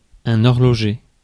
Ääntäminen
Ääntäminen France: IPA: [ɔʁ.lɔ.ʒe] Haettu sana löytyi näillä lähdekielillä: ranska Käännös Substantiivit 1. watchmaker 2. clockmaker 3. horologist Adjektiivit 4. horological Suku: m .